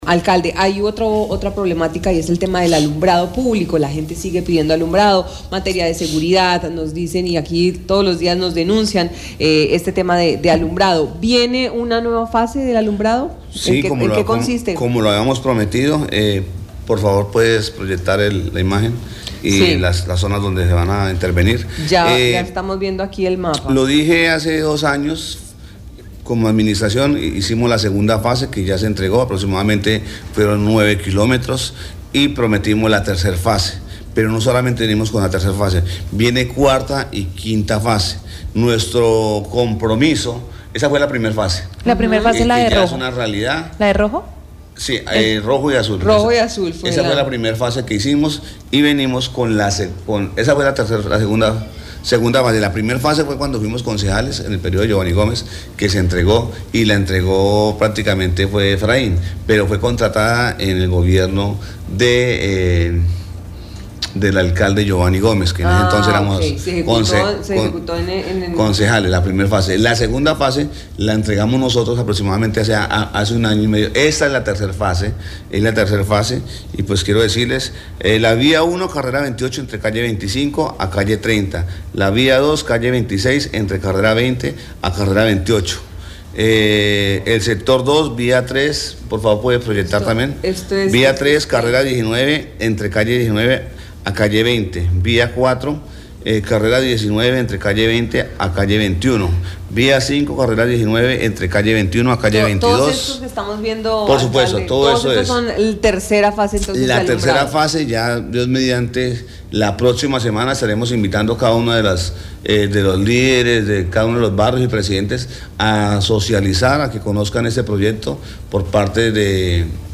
El alcalde Ramón Guevara, explicó cuáles serán las rutas a seguir en la ejecución de la tercera fase del alumbrado público, además anunció que se ejecutarán la tercera y cuarta fase del alumbrado con un costo aproximadamente de 2.130 millones de pesos, con una cobertura total de 9,6 kilómetros.